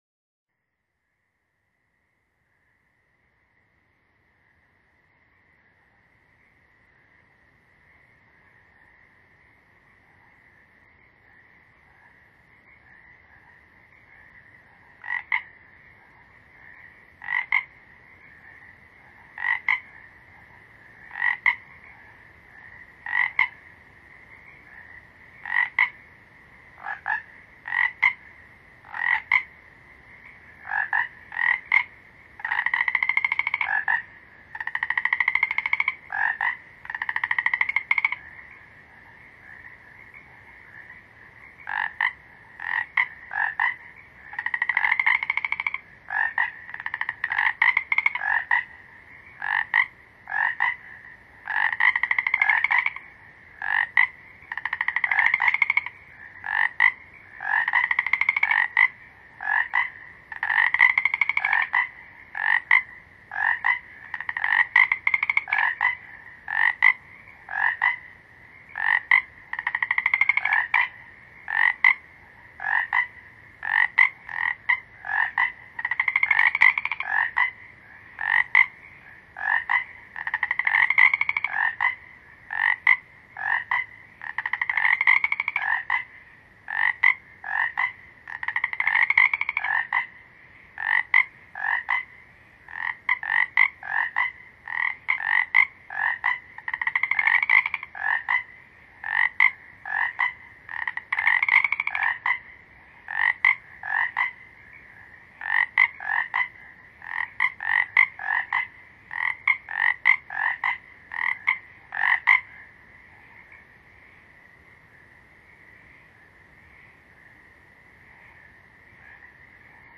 这是大自然天籁的声音，没有掺杂任何的乐器。